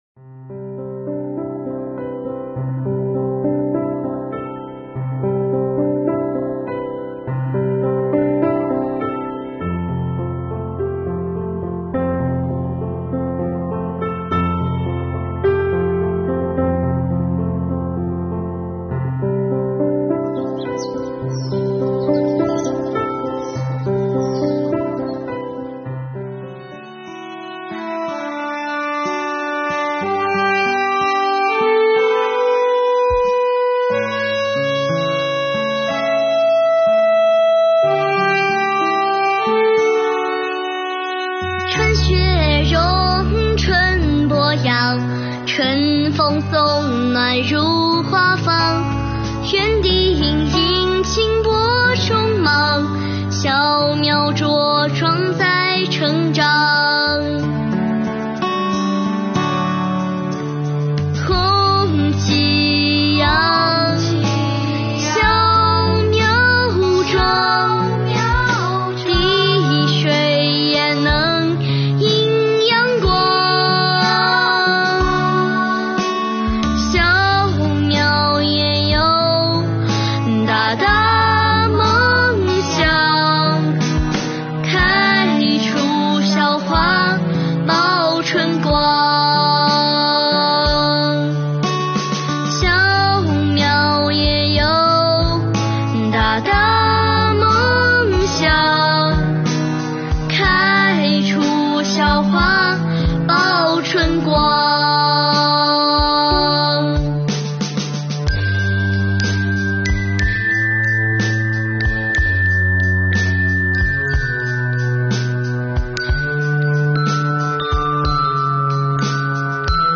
伴着歌声，让我们同“她”共同回顾税宣30年，见证我国税收事业高质量发展的历程，唱响“税收惠民办实事 深化改革开新局”的好声音。